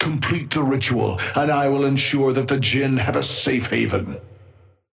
Amber Hand voice line - Complete the ritual and I will ensure that the Djinn have a safe haven.
Patron_male_ally_mirage_start_04.mp3